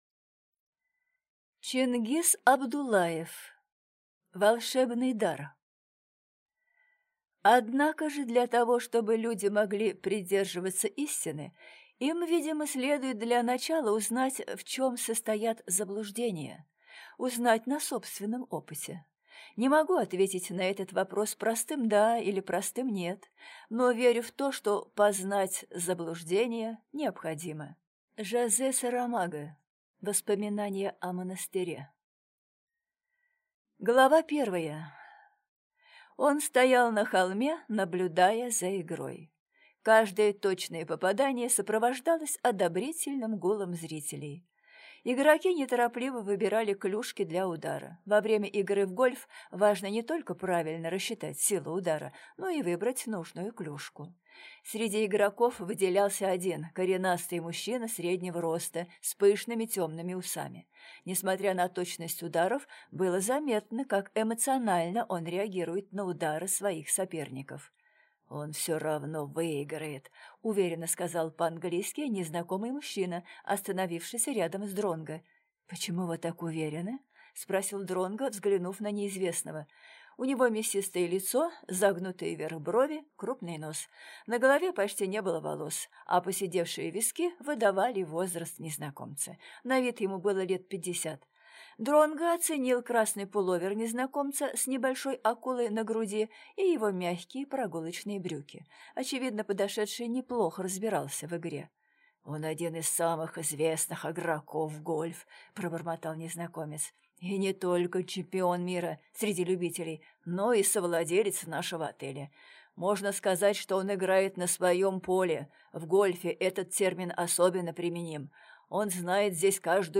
Аудиокнига Волшебный дар | Библиотека аудиокниг